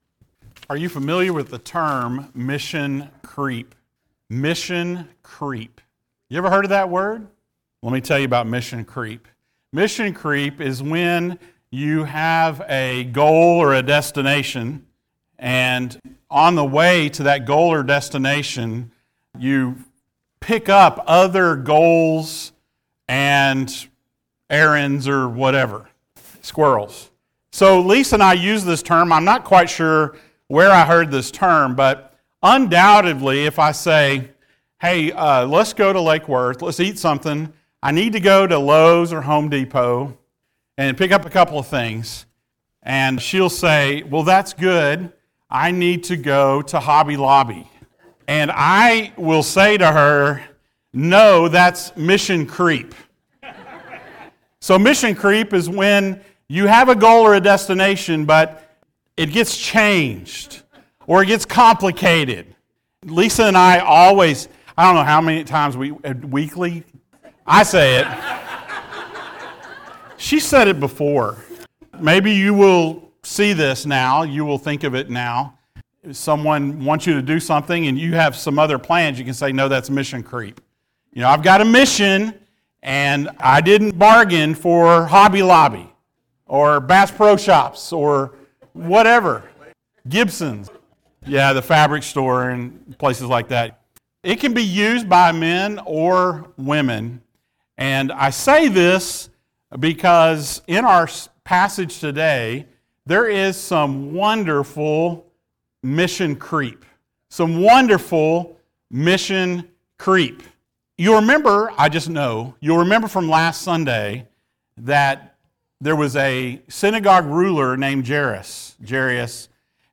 The Life and Ministry of Jesus Passage: Mark 5:24-34 Service Type: Sunday Morning Thank you for joining us.